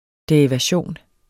Udtale [ deɐ̯ivaˈɕoˀn ]